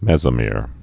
(mĕzə-mîr, mĕs-)